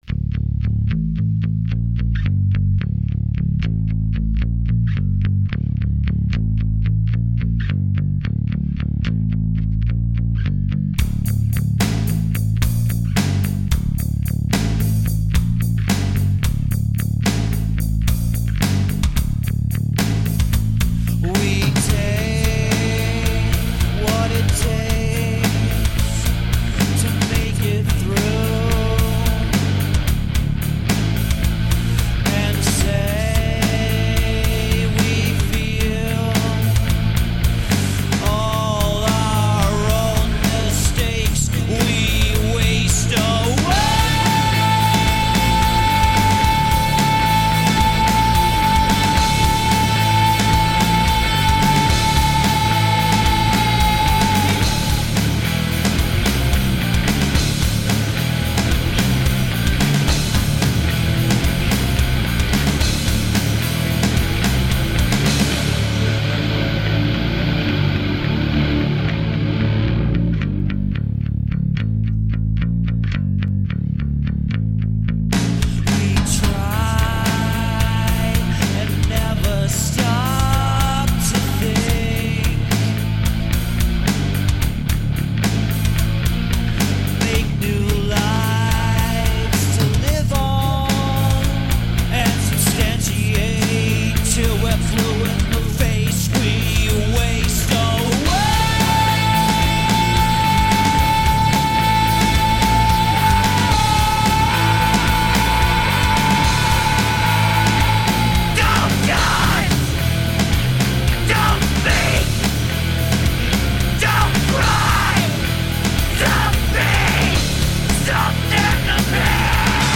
Heavy modern rock..
New York metal trio
Asymmetric grooves stay low to the ground
Tagged as: Hard Rock, Punk, High Energy Rock and Roll